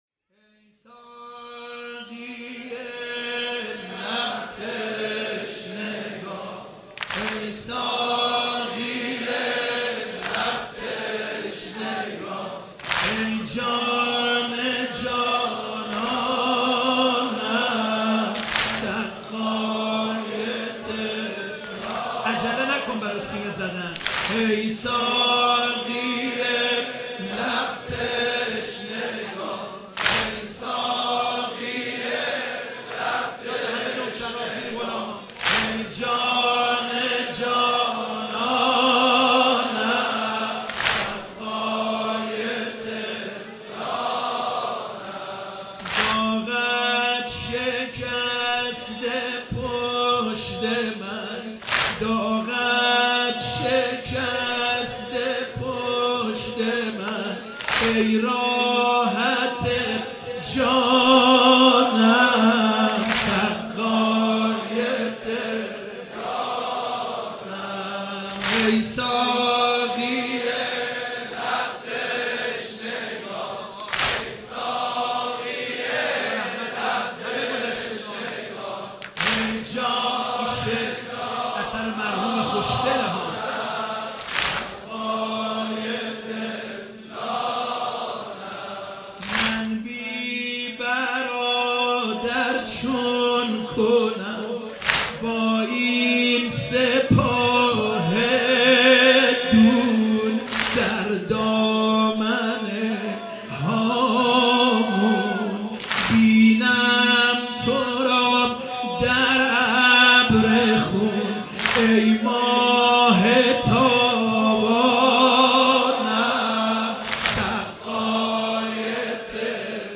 مداحی صوتی